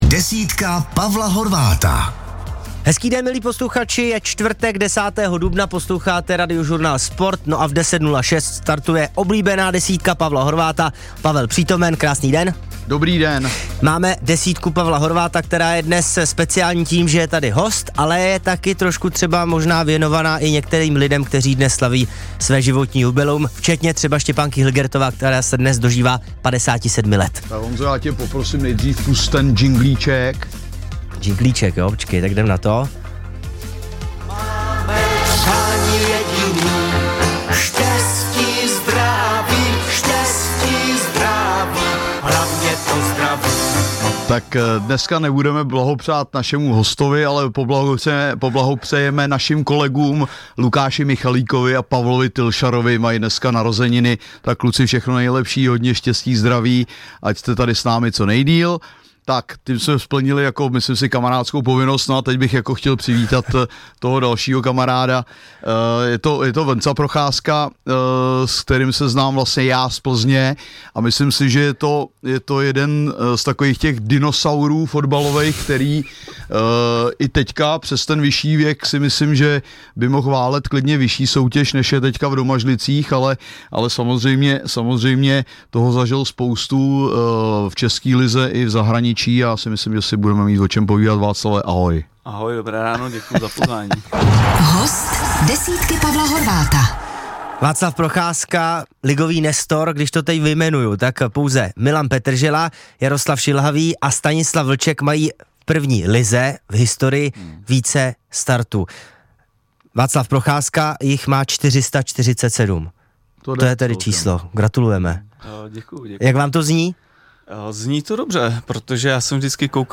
Na place: Hosty hlavně ze sportovního prostředí zvou do studia přední čeští herci známí např. z rolí v seriálu a filmu Okresní přebor a sportovní fanoušci - 09.04.2025